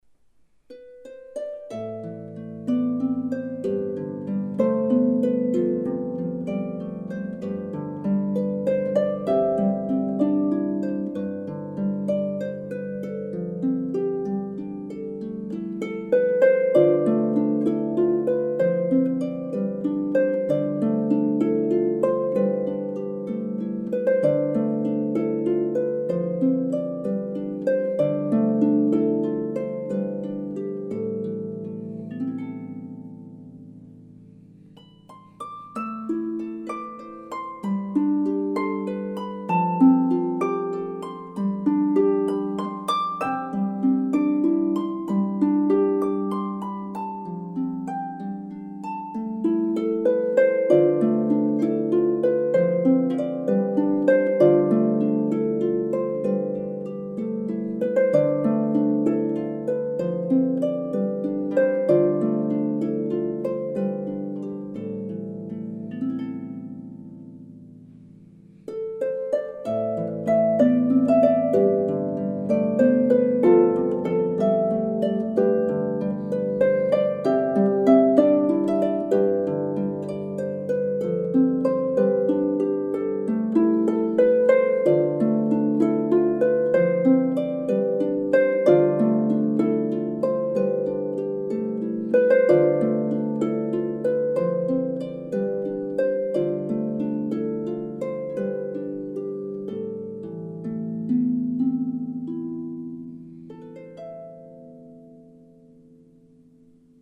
traditional French tune
solo lever or pedal harp